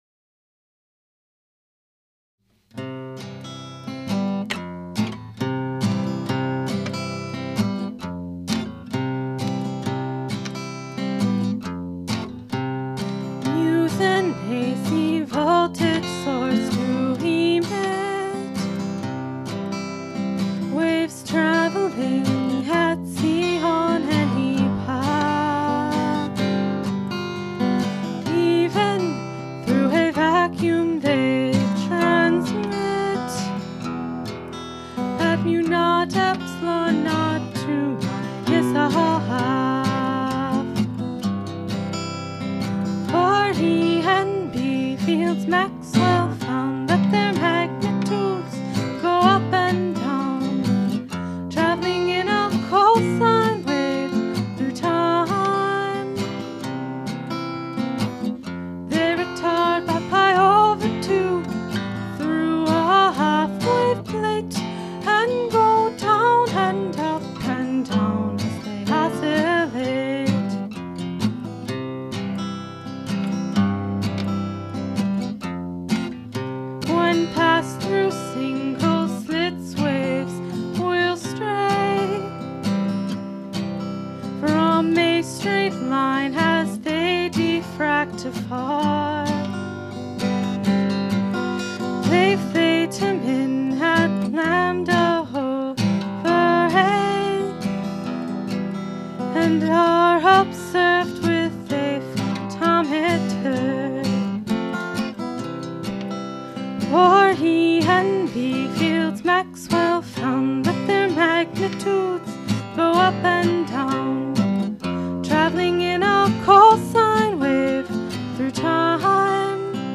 vocal
Guitar